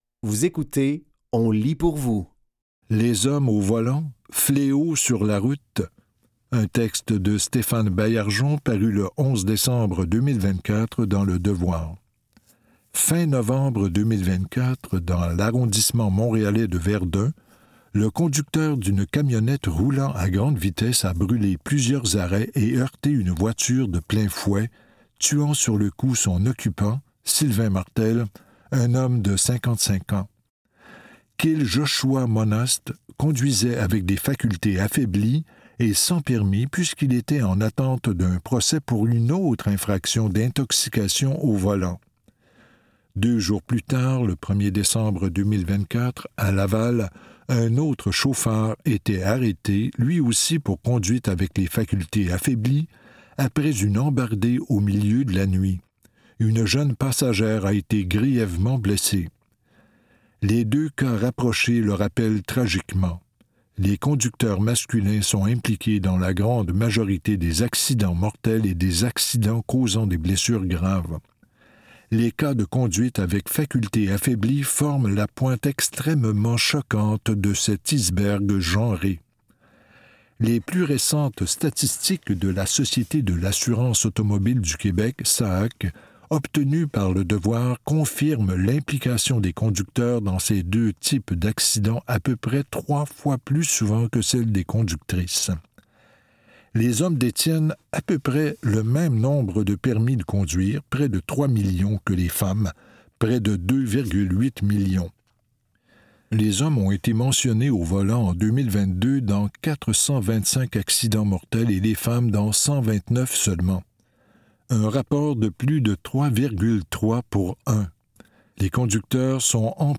Dans cet épisode de On lit pour vous, nous vous offrons une sélection de textes tirés des médias suivants : Le Devoir, Le Citoyen et La Presse.